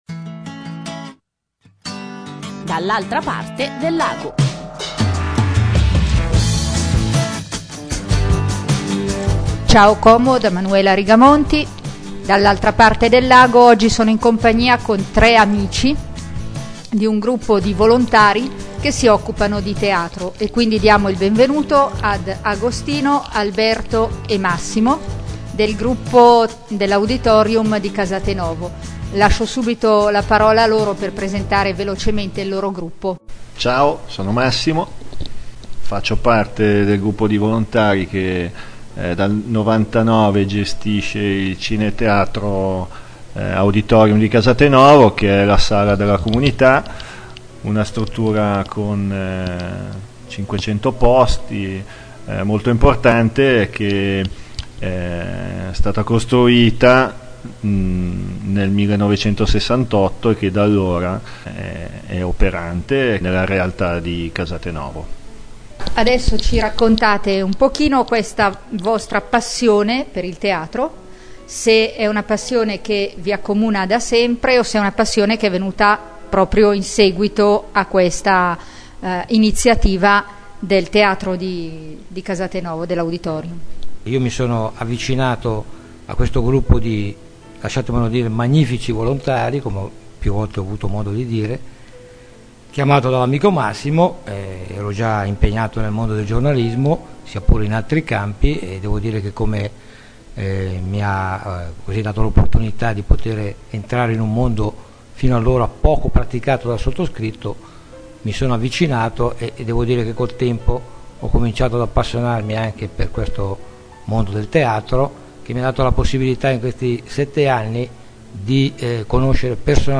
L'intervista ad alcuni volontari
ciao-como-intervista.mp3